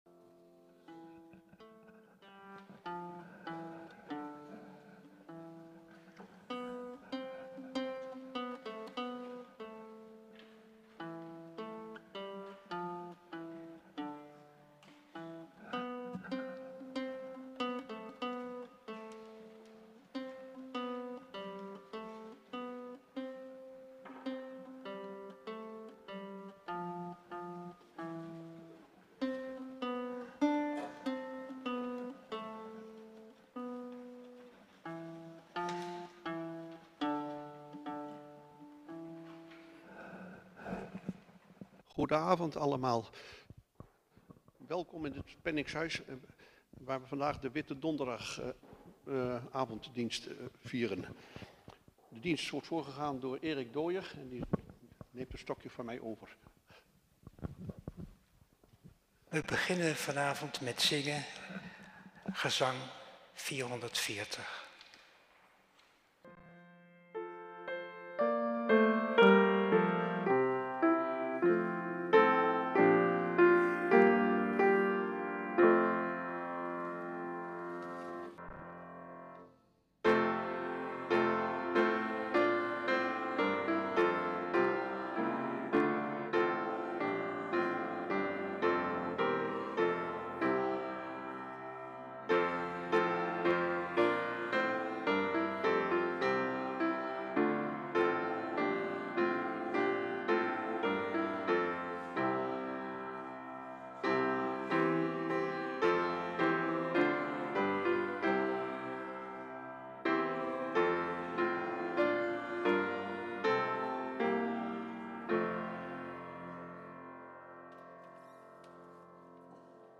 avondmaalviering witte donderdag